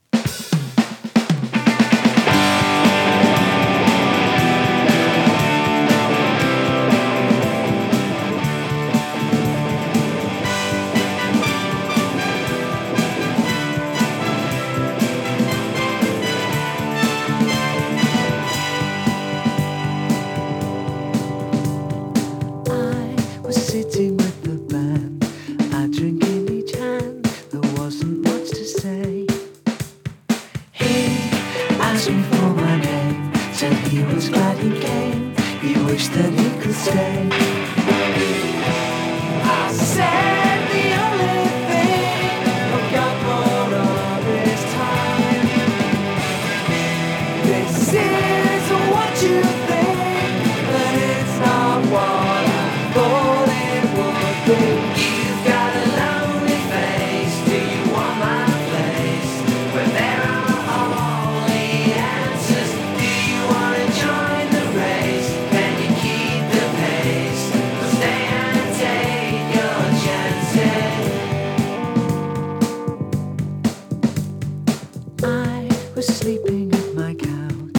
ブリットポップ
試聴はLPからの流用。